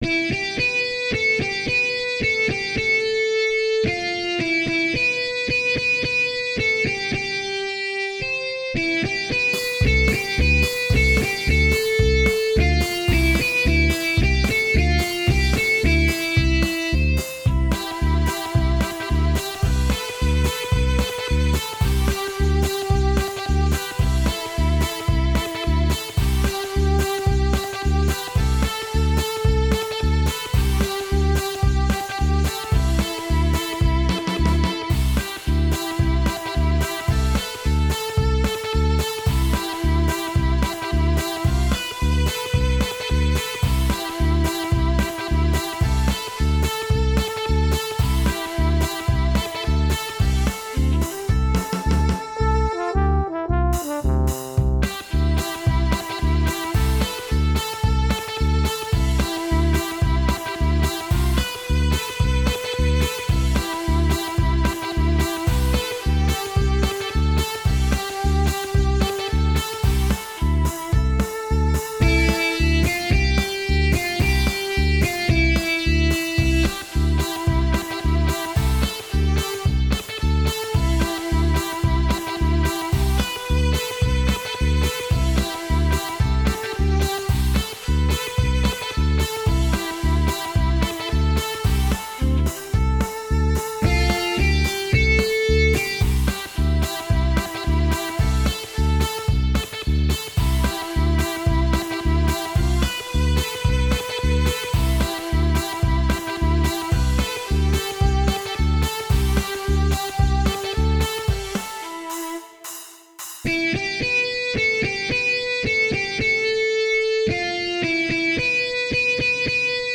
с помощью компьютера и синтезатора
Минусовка (для караоке)